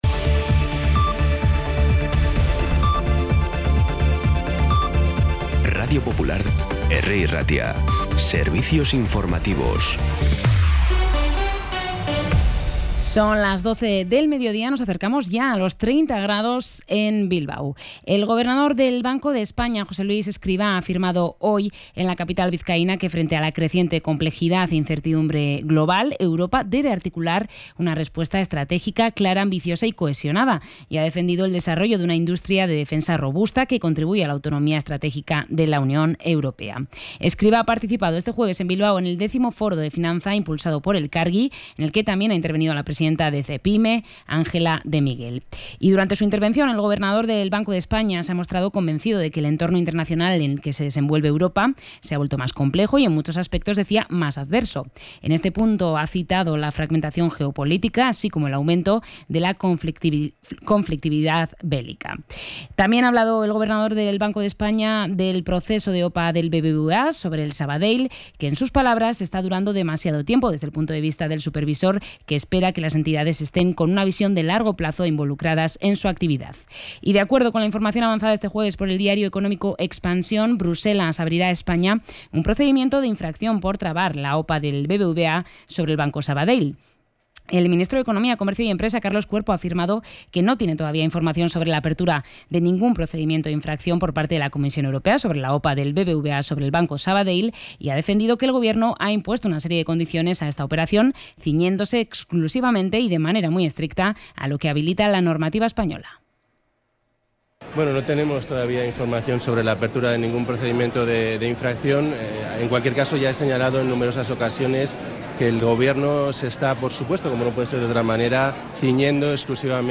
Podcast Bizkaia